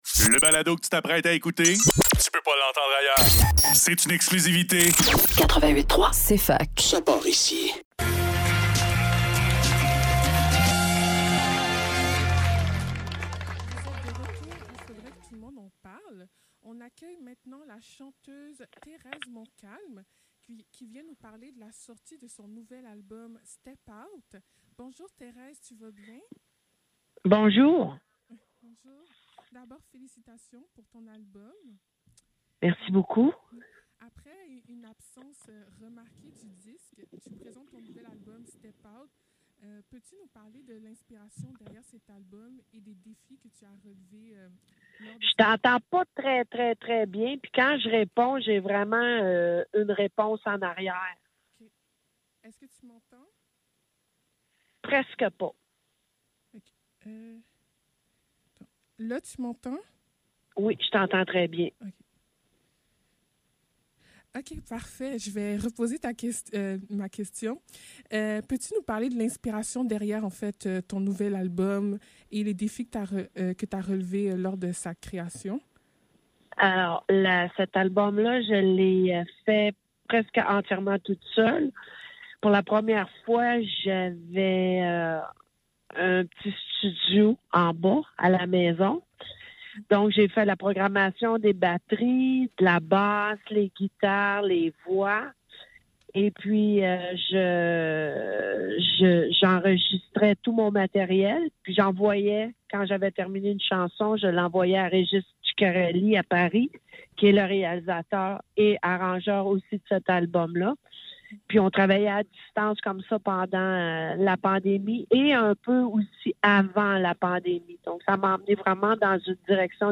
Faudrait que tout l'monde en parle - Entrevue avec Terez Montcalm - 29 février 2024